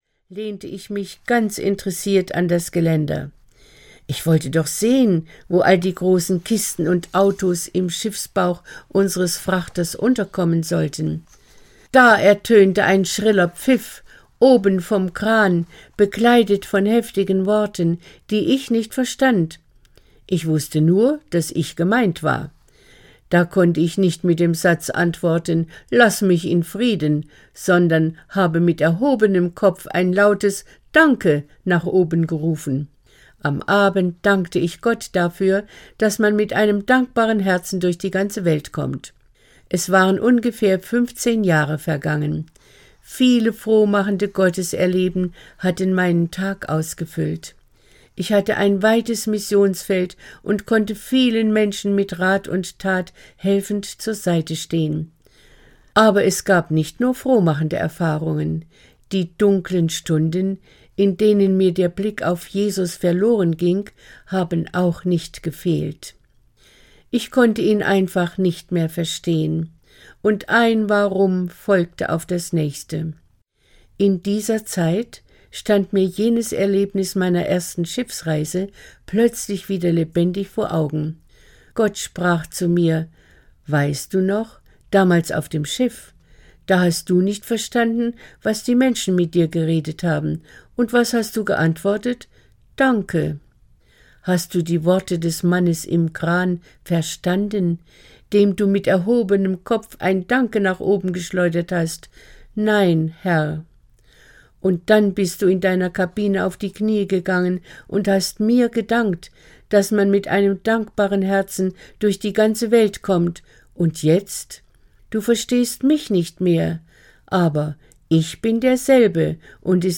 Die Urwaldhebamme - Ilse Roennpagel - Hörbuch